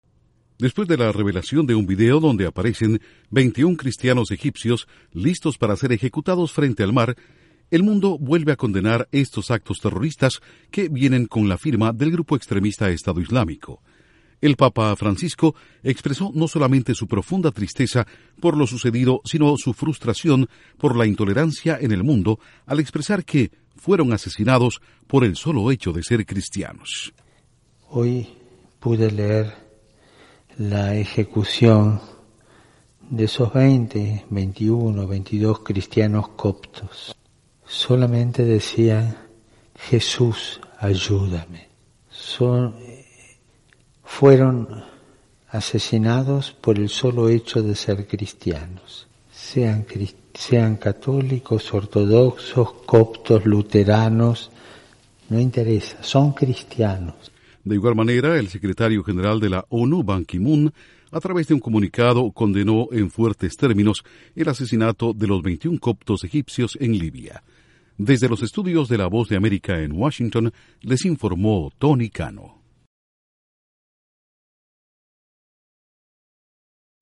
Papa Francisco dice que Coptos egipcios fueron asesinados solo por ser cristianos. Informa desde los estudios de la Voz de América en Washington